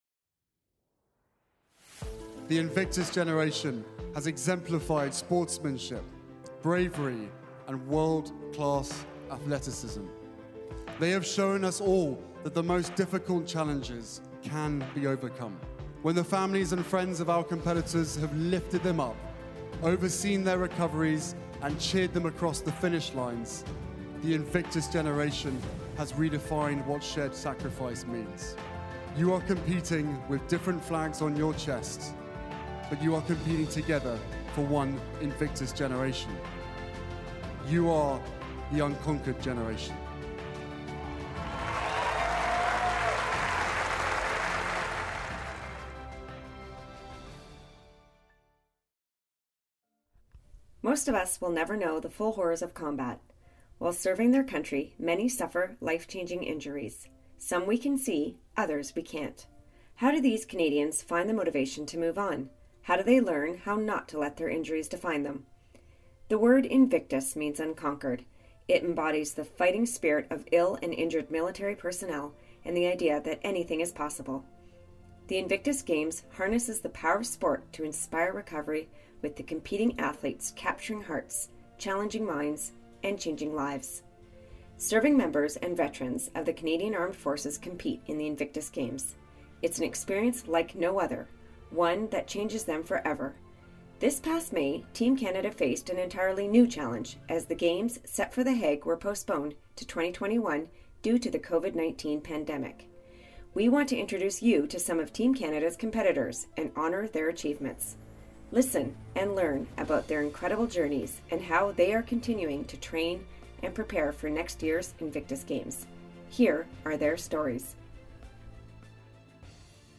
These podcasts were then recreated in the alternate official language using voiceovers.